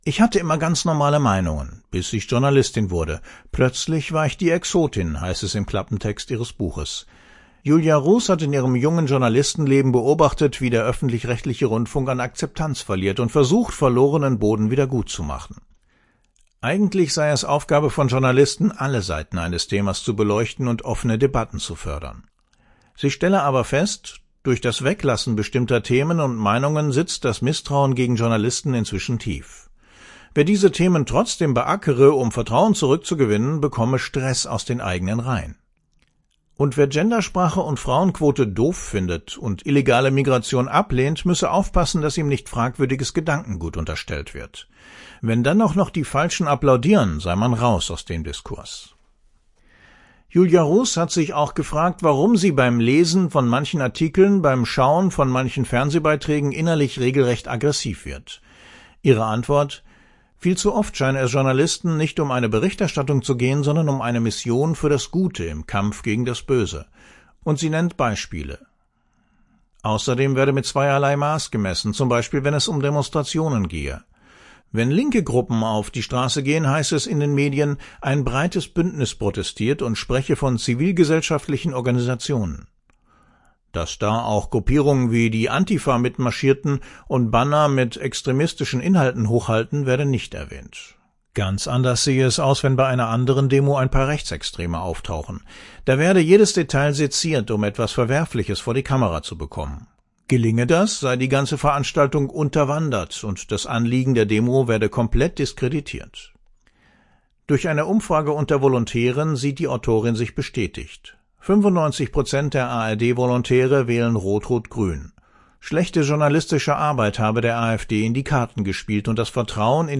Buchvorstellung „Links-grüne Meinungsmacht - Die Spaltung unseres Landes“ v. Julia Ruhs